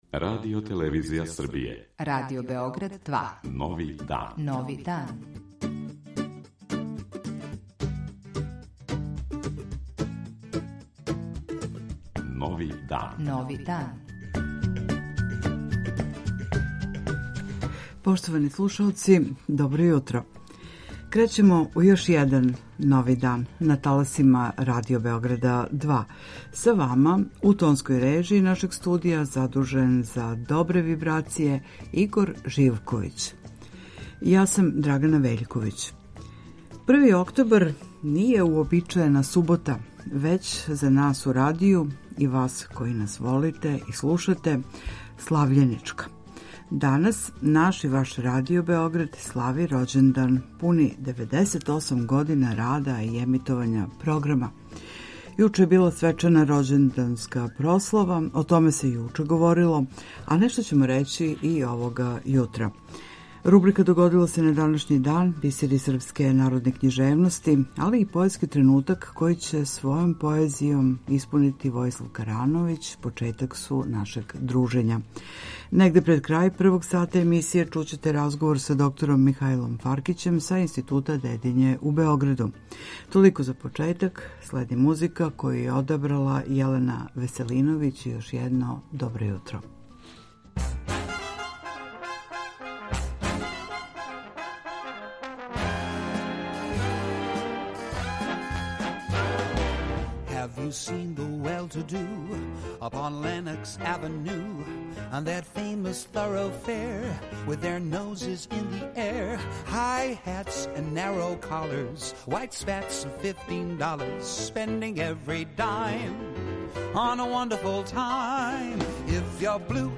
Доносимо запис са тог догађаја. У рубрици о храни говоримо о коштуњавом воћу и њиховој корисности за наш организам.